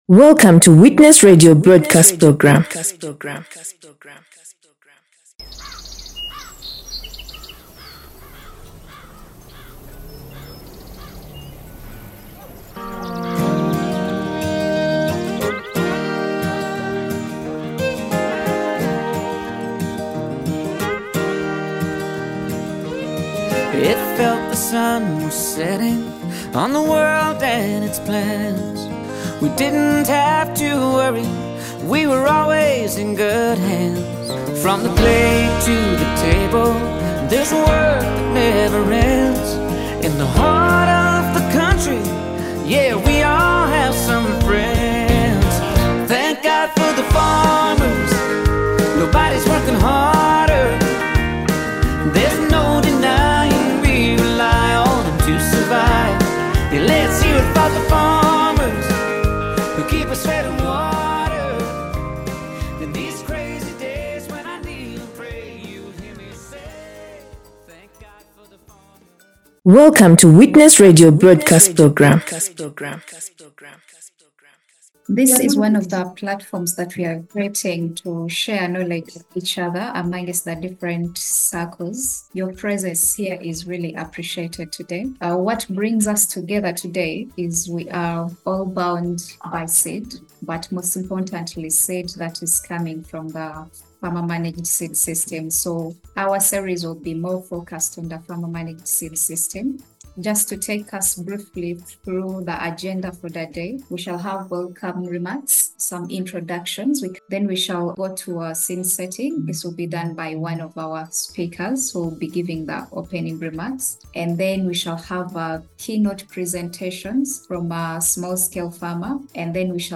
ESAFF webinar on boosting crop production with organic seeds.(1).mp3